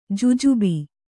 ♪ jujubi